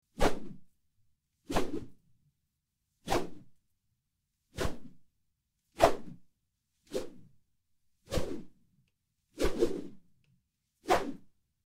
10. Взмахи веревкой с соответственными звуками для монтажа видео